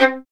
Index of /90_sSampleCDs/Roland L-CD702/VOL-1/STR_Violin 1-3vb/STR_Vln2 % + dyn